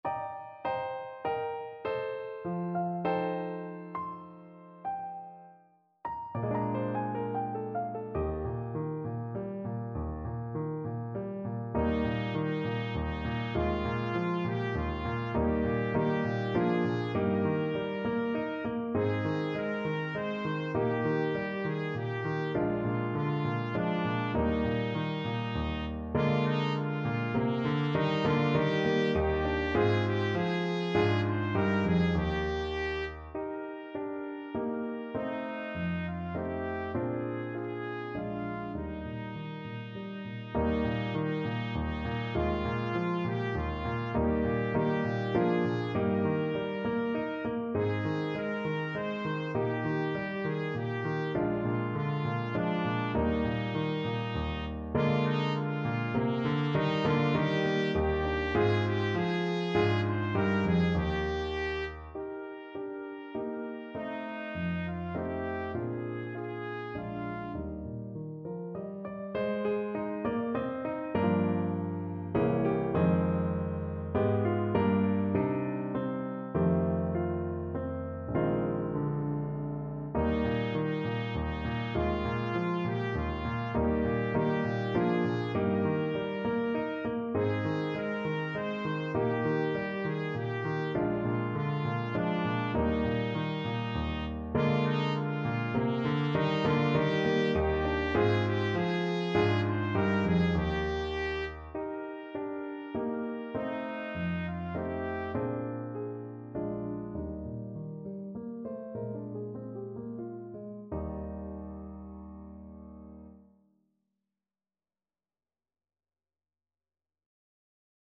Trumpet version
Childrens
Tempo rubato
3/4 (View more 3/4 Music)
Trumpet  (View more Easy Trumpet Music)
Traditional (View more Traditional Trumpet Music)